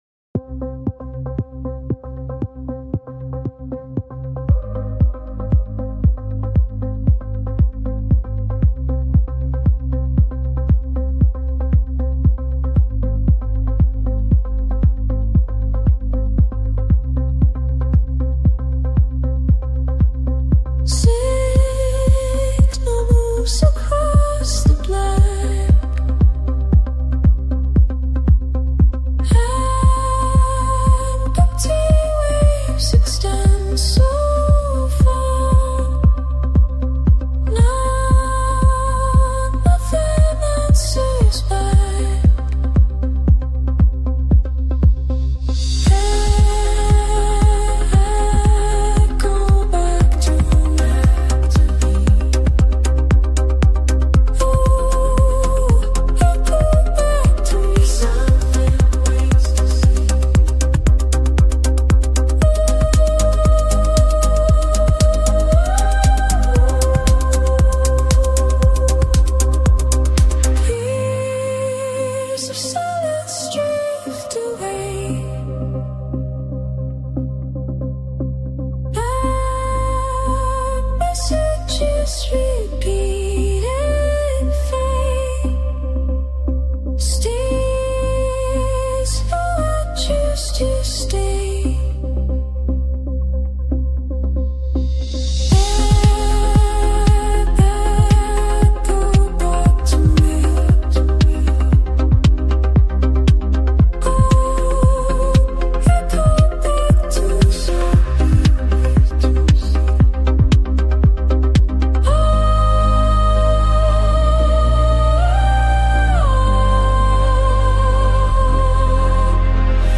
a cosmic symphony of light and sound